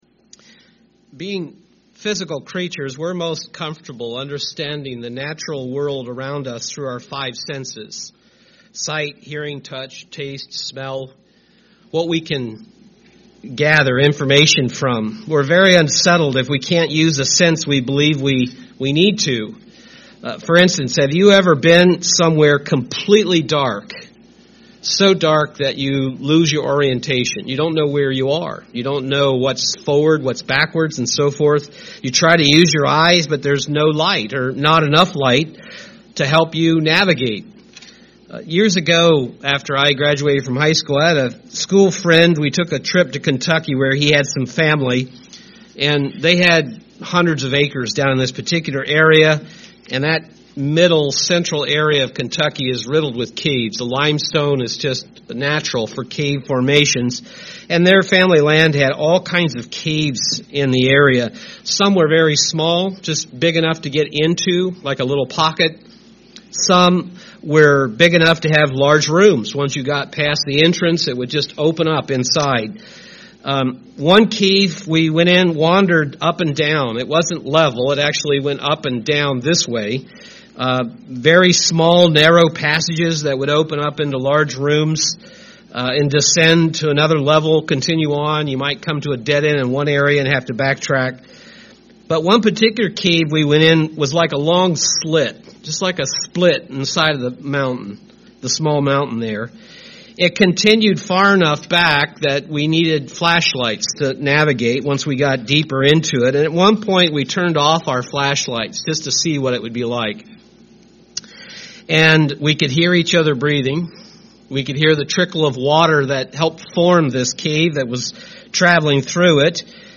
Given in Milwaukee, WI
UCG Sermon Studying the bible?